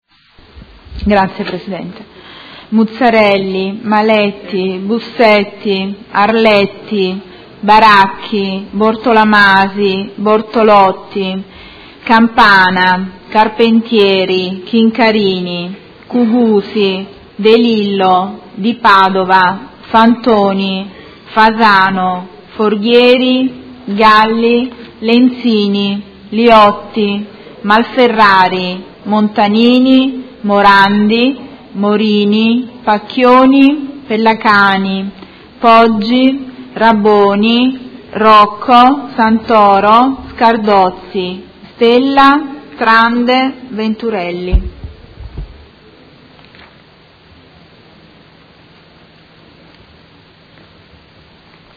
Seduta del 18/05/2017. Appello
Segretario Generale